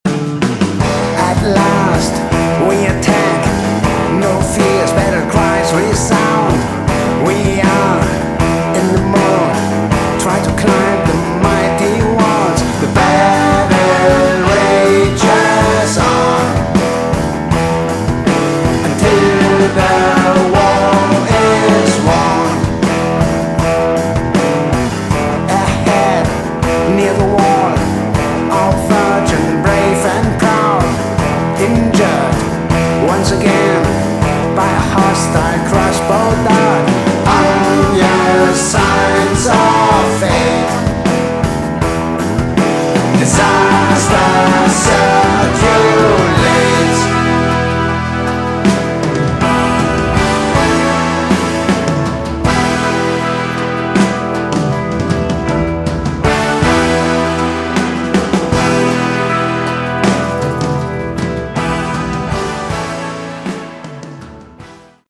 Category: Prog Rock
vocals, guitars, backing vocals
drums, percussion